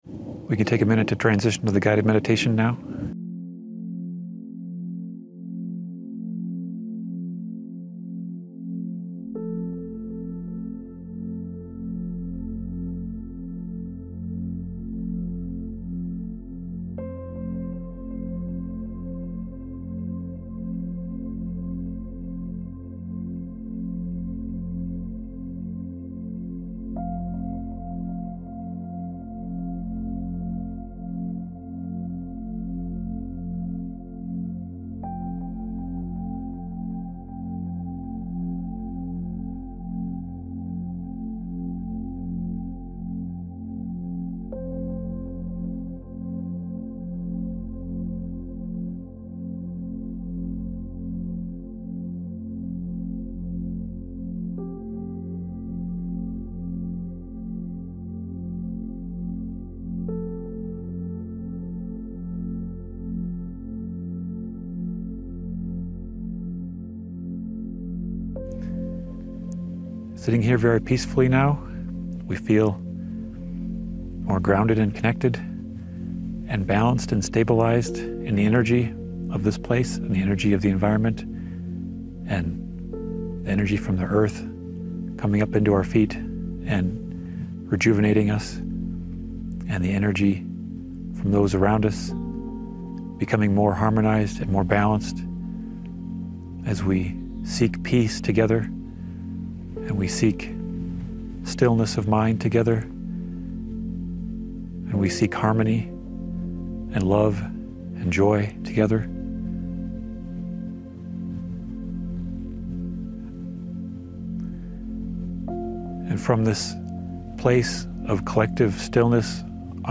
Participants engage in a deep guided meditation to connect with the earth's energies, align with universal consciousness, and promote global healing and unity.